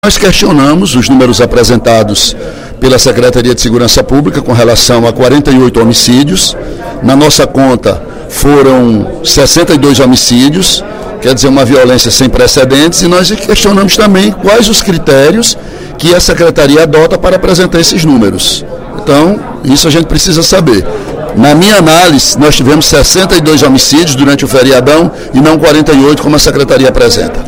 Durante o primeiro expediente da sessão plenária desta terça-feira (07/04), o deputado Ely Aguiar (PSDC) afirmou que contabilizou a ocorrência de 62 homicídios no Estado do Ceará durante o feriado da Semana Santa.